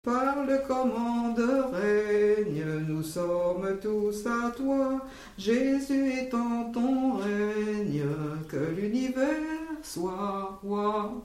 chanté à la mission de 1945
Genre strophique
Pièce musicale inédite